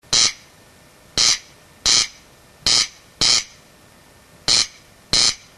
Audio File of the corncrake's distinctive call (photo and sound originally from BirdLife International Corncrake Conservation Team).